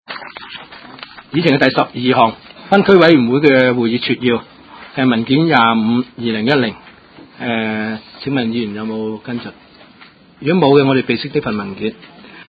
灣仔區議會第十五次會議
灣仔民政事務處區議會會議室